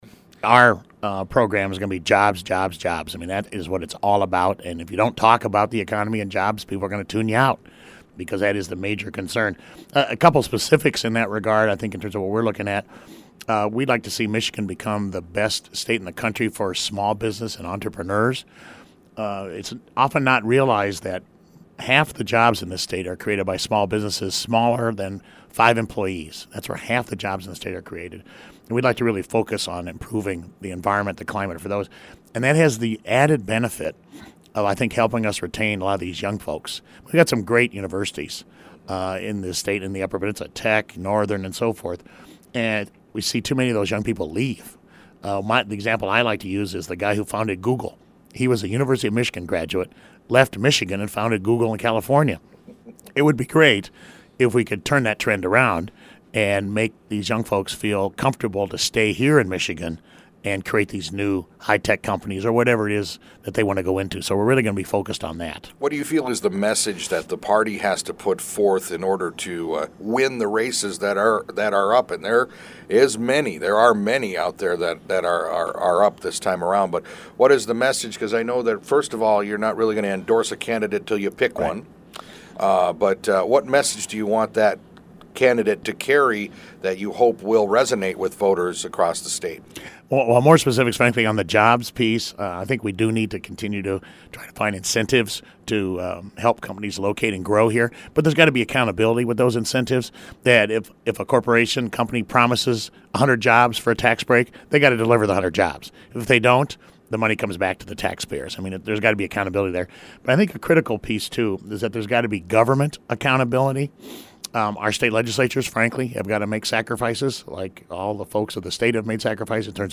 We had a chance to sit down with him for a few moments to get his perspectives on the upcoming 2010 elections and how they were going to go. We also talked about the impacts the outcomes of the elections could have on the region, state and nation.